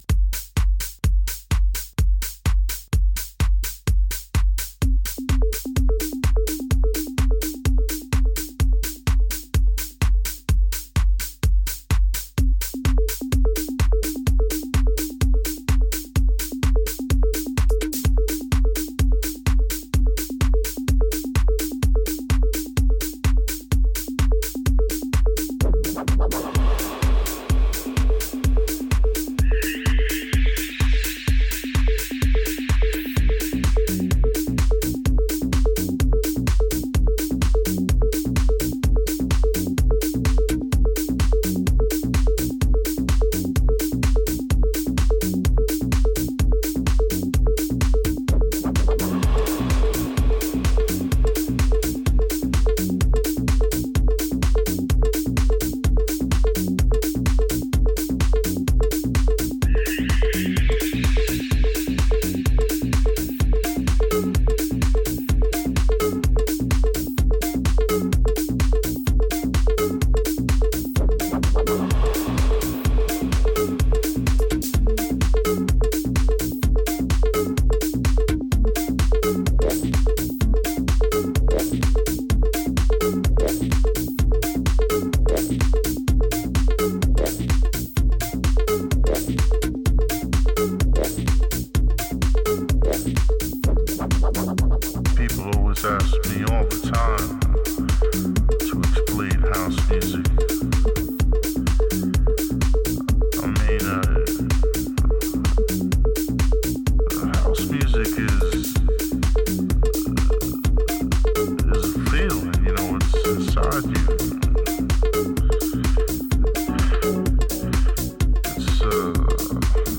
live impeccable à 3 platines
Techno tendance minimale